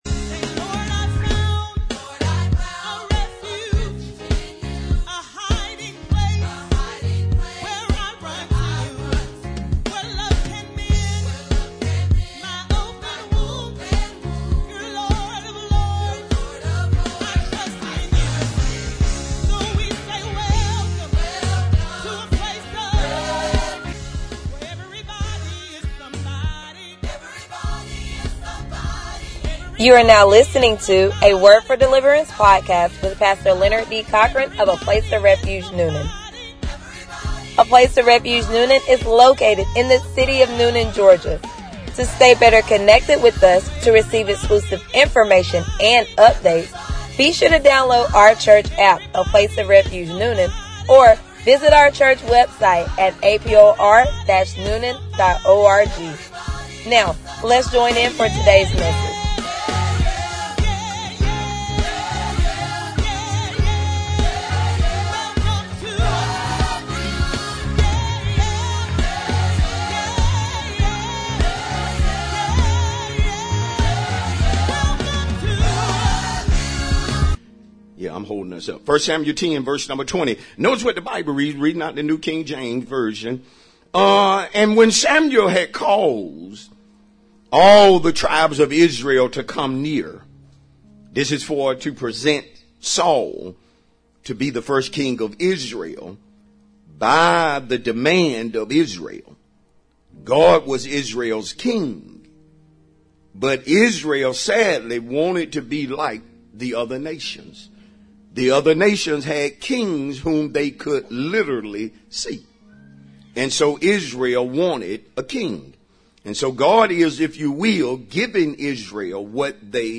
Sermons | A Place Of Refuge Newnan